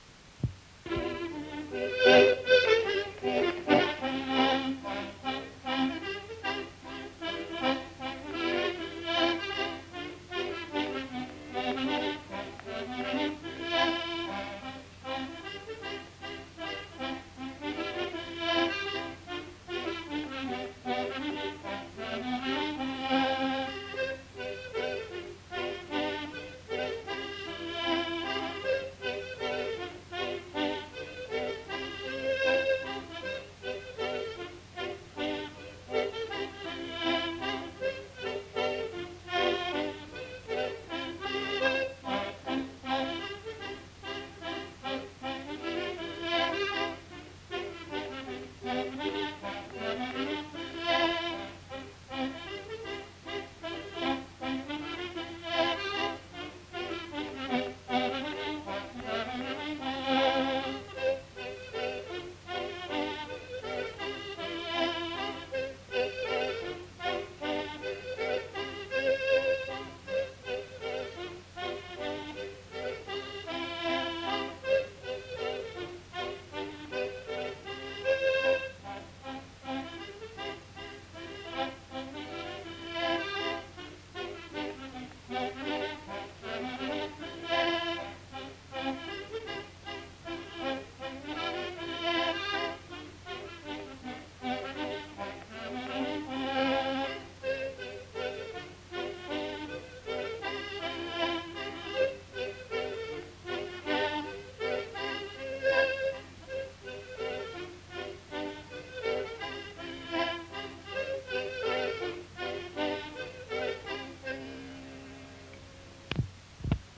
Lieu : Villardonnel
Genre : morceau instrumental
Instrument de musique : accordéon diatonique
Danse : mazurka
Ecouter-voir : archives sonores en ligne